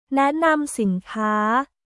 แนะนำสินค้า　ナエナム　スィンカー